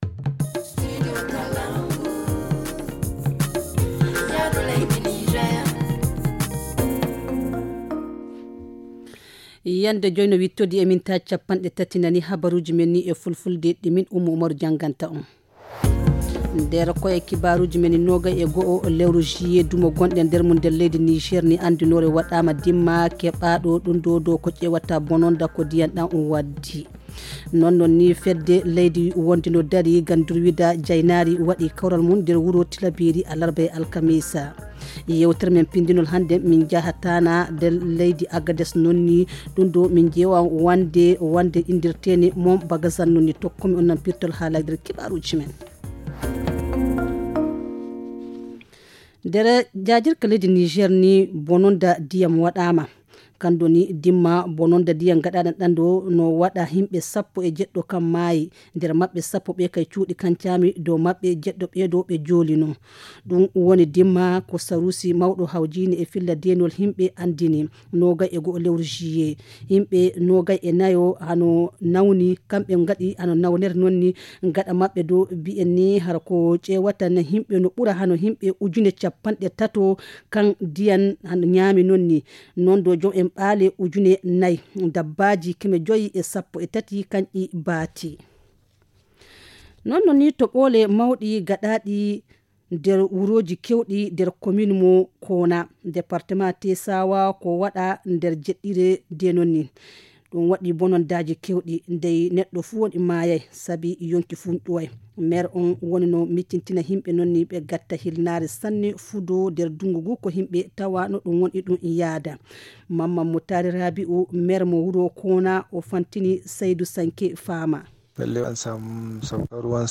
Le journal du 22 juillet 2022 - Studio Kalangou - Au rythme du Niger